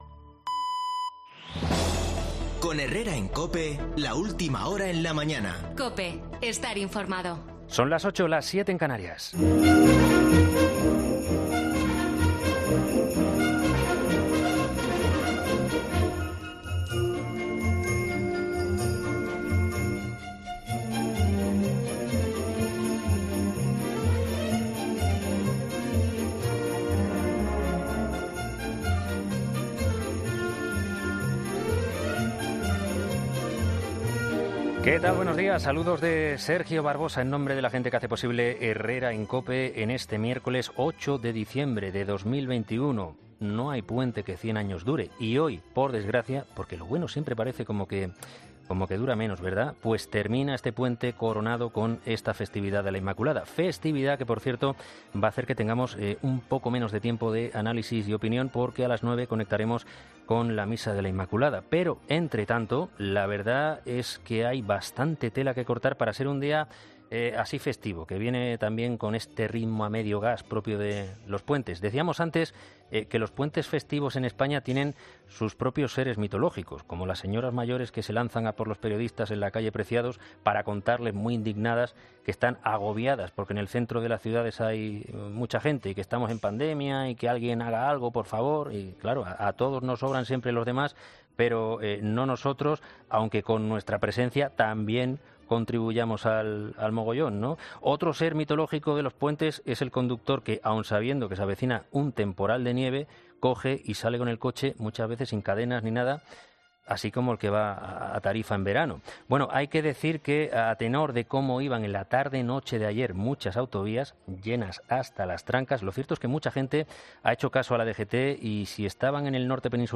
Final del puente de la Inmaculada, la vacunación para los más pequeños y la situación del trabajo estacional. El editorial